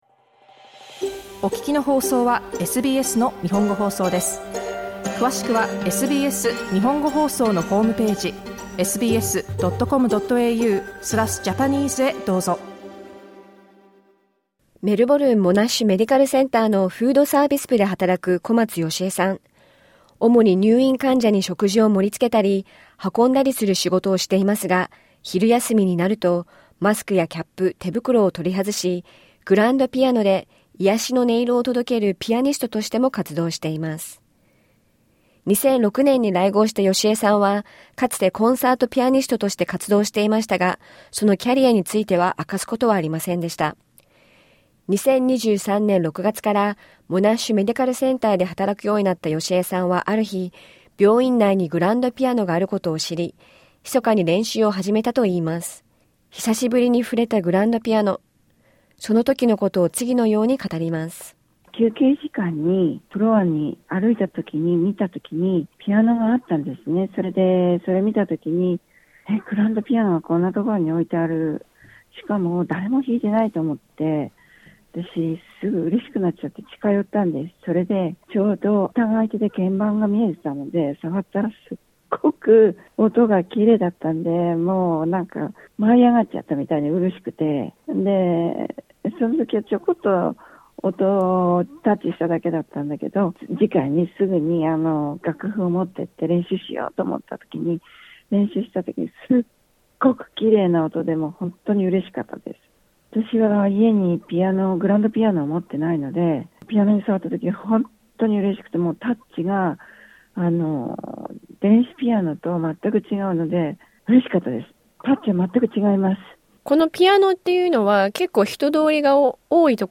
今回のインタビュー、SBSの Small Business Secret で取り上げたものを日本語でお送りしました。